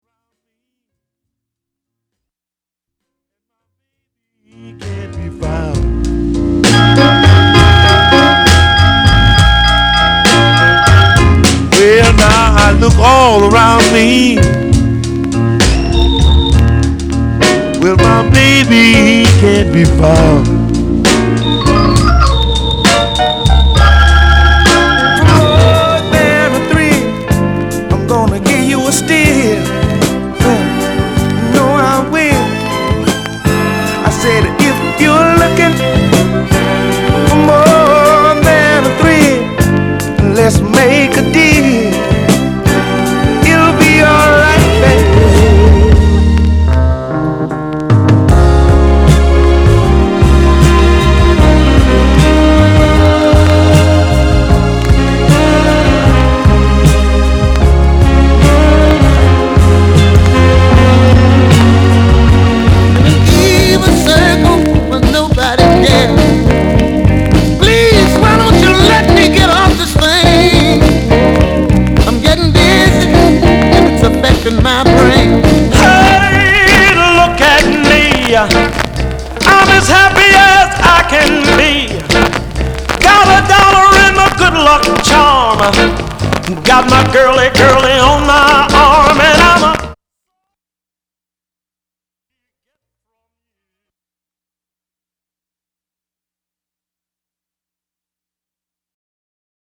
category Blues